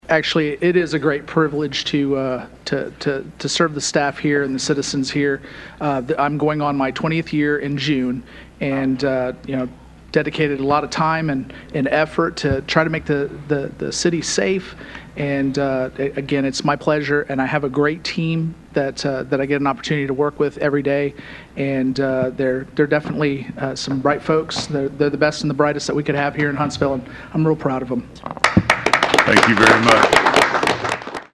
addressed the council audience.